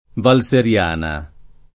vai all'elenco alfabetico delle voci ingrandisci il carattere 100% rimpicciolisci il carattere stampa invia tramite posta elettronica codividi su Facebook Val Seriana [ v # l S er L# na ] o Valseriana [ id. ] top. f. (Lomb.) — la valle del fiume Serio